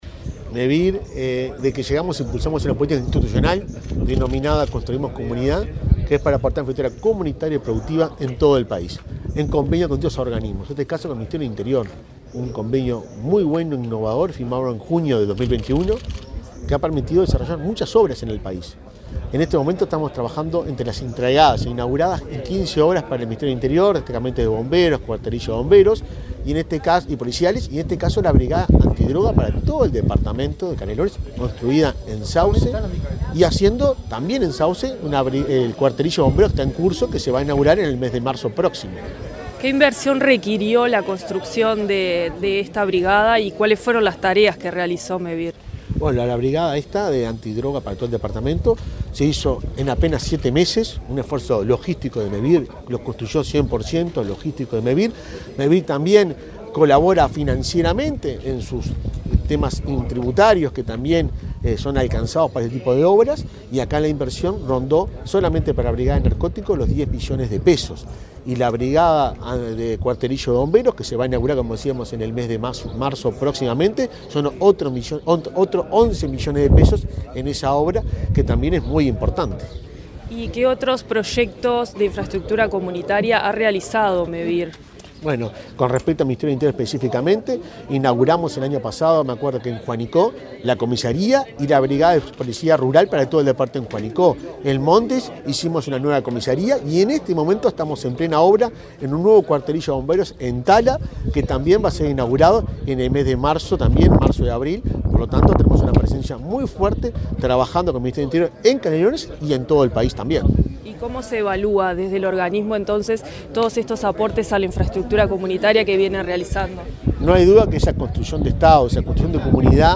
Entrevista al presidente de Mevir, Juan Pablo Delgado
Entrevista al presidente de Mevir, Juan Pablo Delgado 20/12/2023 Compartir Facebook X Copiar enlace WhatsApp LinkedIn Este miércoles 20, el presidente de Mevir, Juan Pablo Delgado, dialogó con Comunicación Presidencial, antes de participar en la inauguración de la Brigada Departamental Antidrogas de la localidad de Sauce, departamento de Canelones.